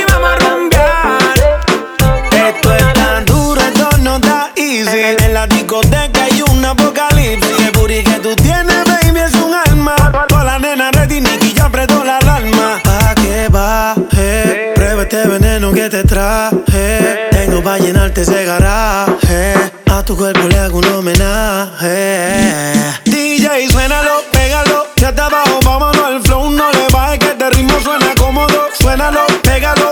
Genre: Urbano latino